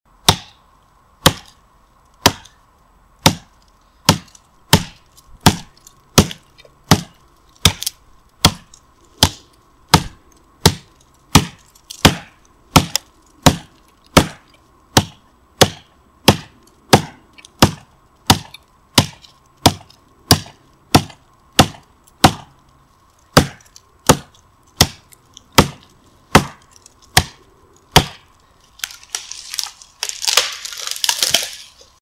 Звуки разрубания
На этой странице собраны реалистичные звуки разрубания — от ударов топором до рубки мечом.
Скоростная рубка деревьев